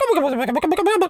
turkey_ostrich_gobble_08.wav